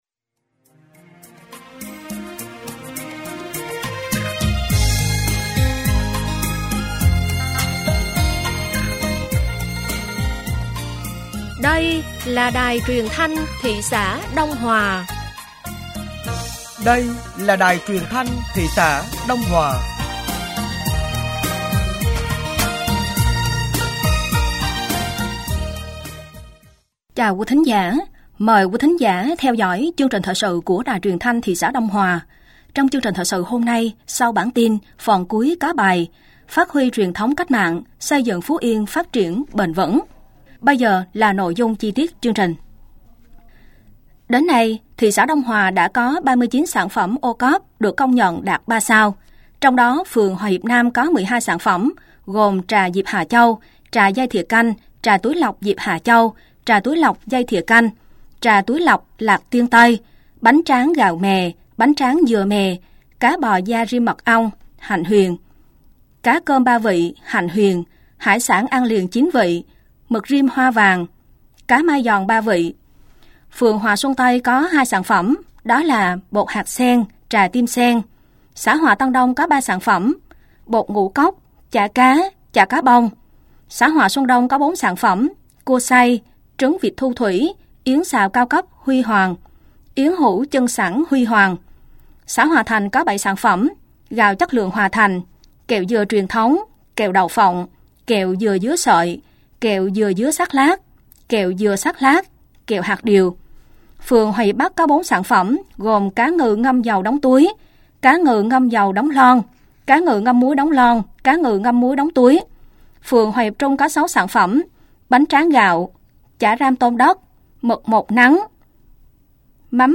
Thời sự tối ngày 19 và sáng ngày 20 tháng 8 năm 2024